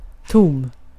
Ääntäminen
IPA: /tʊm/